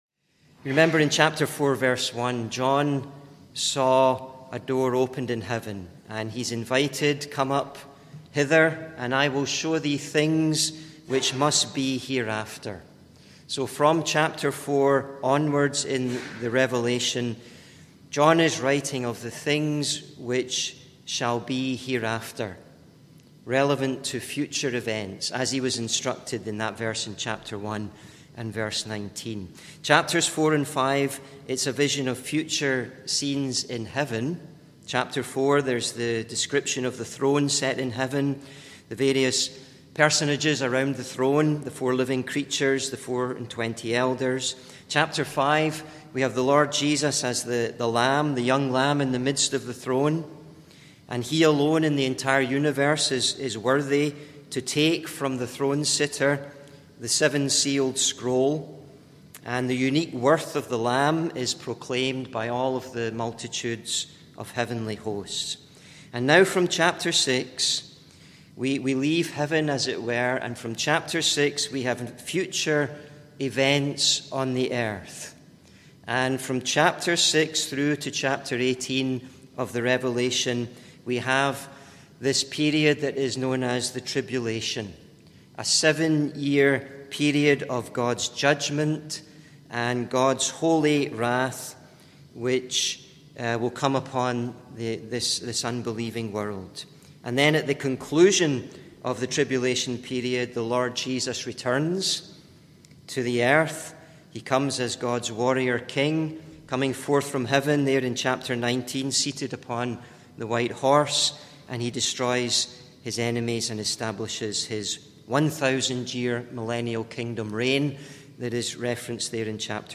He also looks at the 7 trumpet judgments and the 7 bowl judgments. Reading Rev 6:1-2. (Recorded in Parkview Street Gospel Hall, Winnipeg, MB, Canada on 6th Oct 2024)